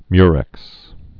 (myrĕks)